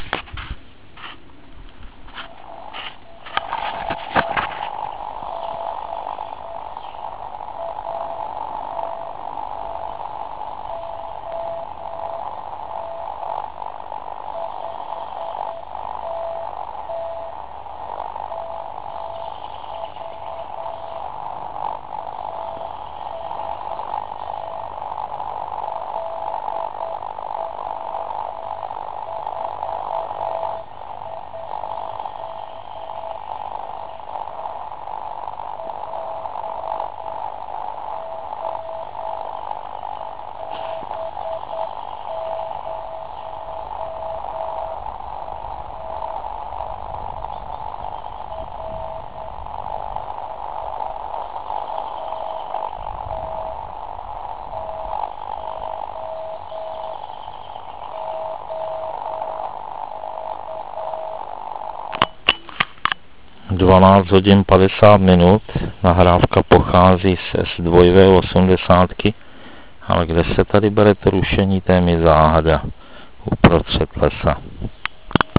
A tak v nahrávkách je možno rozeznat ptačí zpěv. Dosti mne i překvapilo, kde se v lese bere tolik rušení.
Nahrávka z SW80+ (300 KB)
Nahrávky jsou to děsivé. Věřte však, že ten signál byl stoprocentně rozeznatelný.